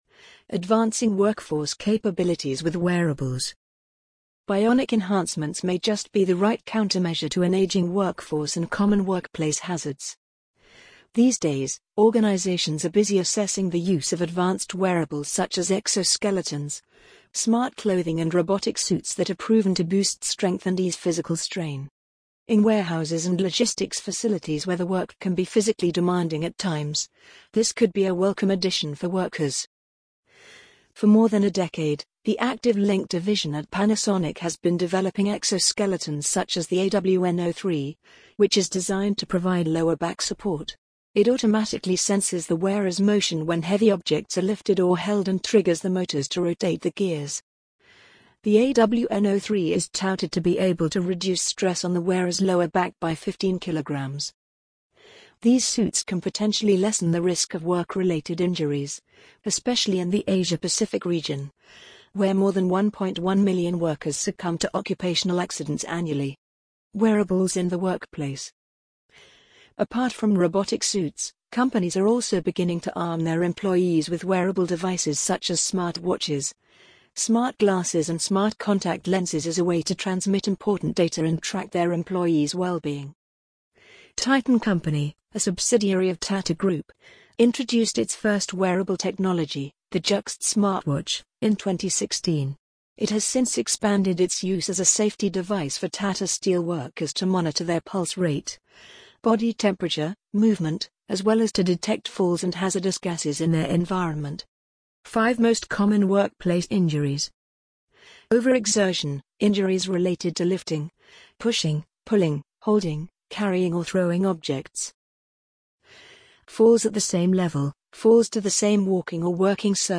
amazon_polly_2586.mp3